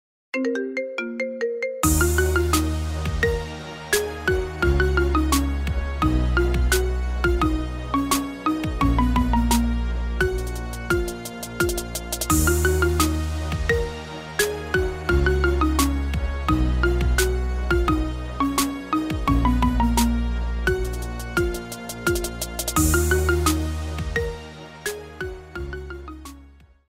Kategoria Marimba Remix